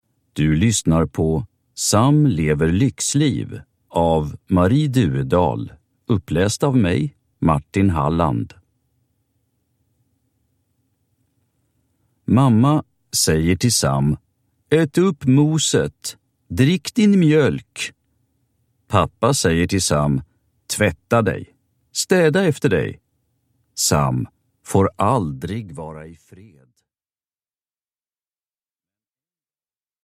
Sam lever lyxliv – Ljudbok